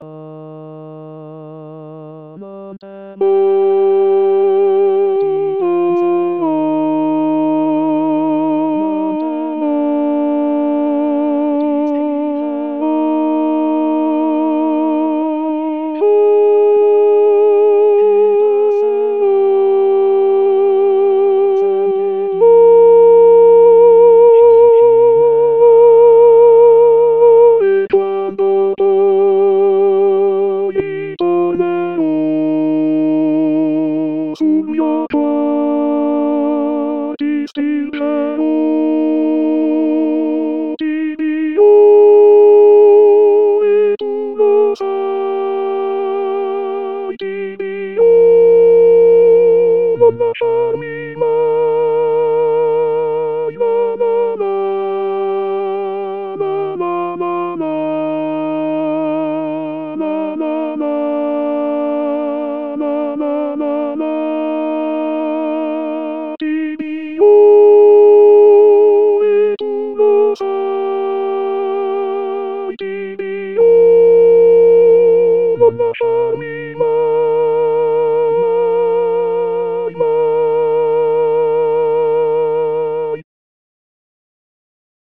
La Ballata del soldato haute contre.mp3